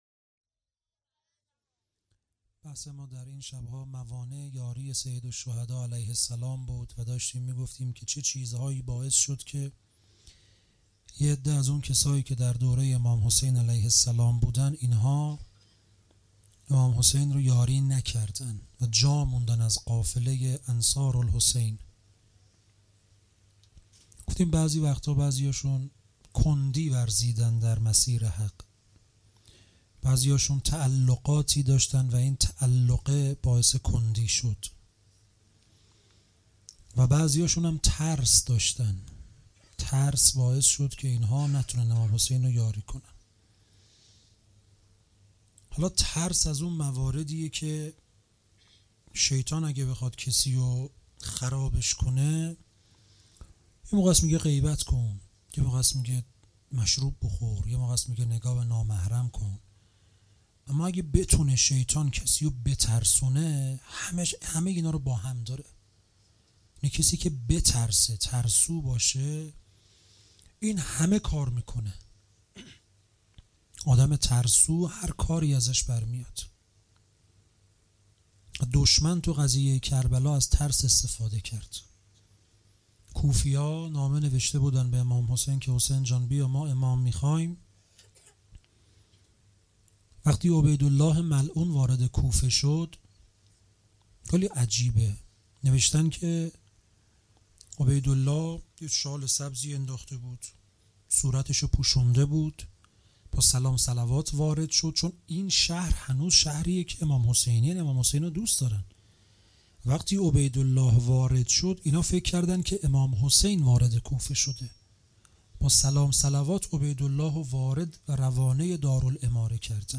هیئت انصارالحسین
سخنرانی